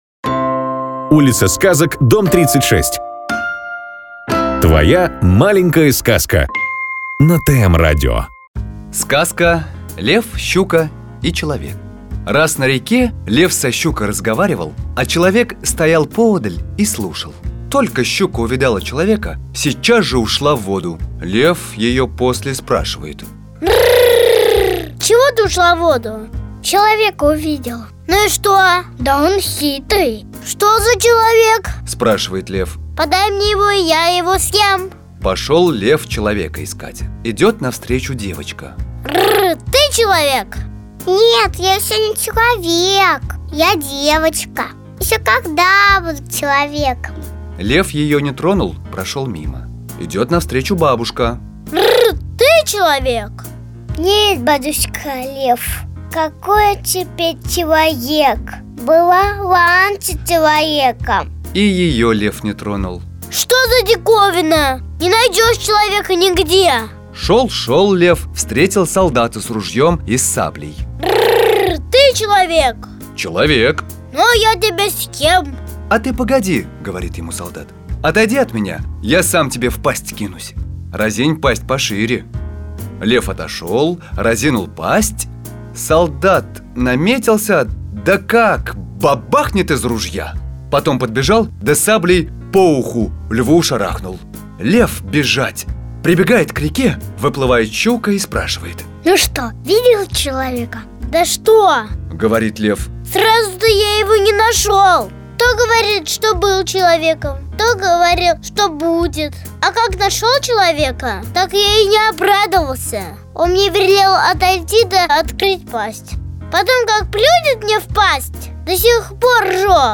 Русская народная аудиосказка «Лев, Щука и Человек» – про человечью хитрость-мудрость. Пришёл Лев к реке, водицы испил.